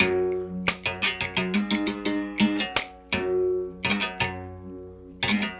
32 Berimbau 05.wav